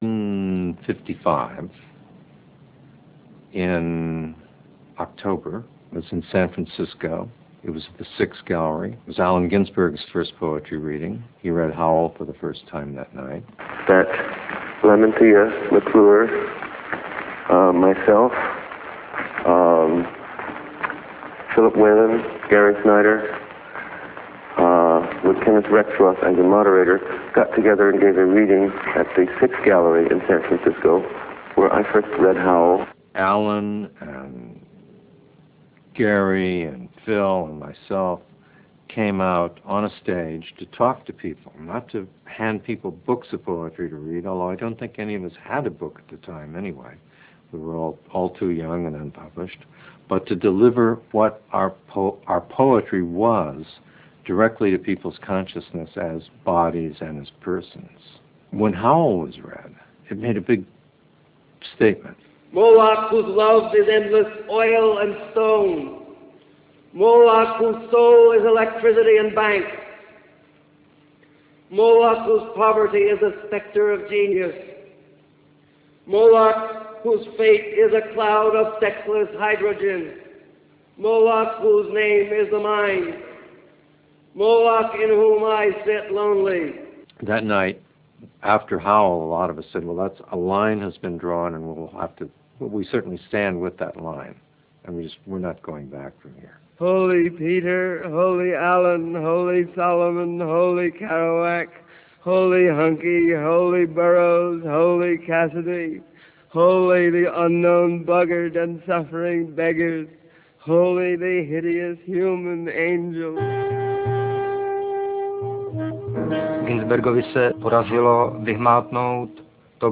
A documentary series about the origin, development and impact of beatniks and the Beat Generation, and reflections on the current validity of their thought.
The series follows three beat generations in the USA, from Allen Ginsberg and Jack Kerouac to Jello Biafra (singer of the punk band Dead Kennedys). Much unique archive material as well as interviews with the still living representatives of the older and younger generations are used throughout.